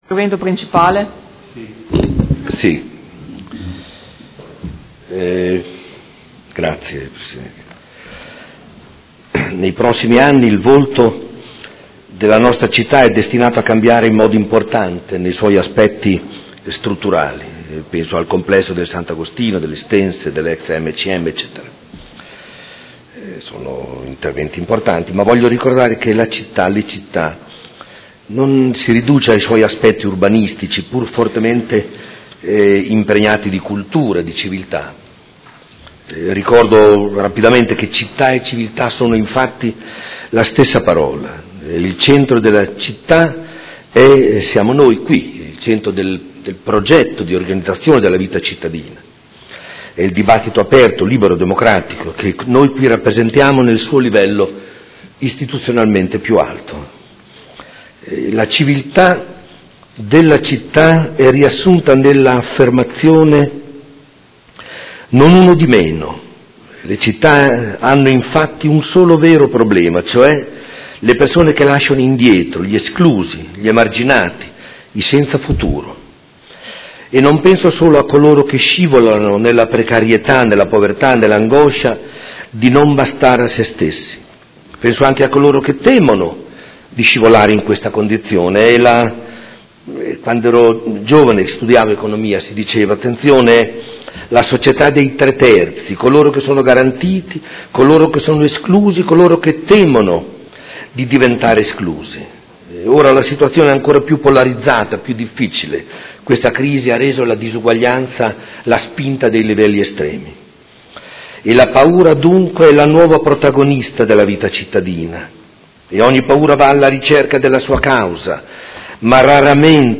Domenico Campana — Sito Audio Consiglio Comunale
Seduta del 26 gennaio. Bilancio preventivo: Dibattito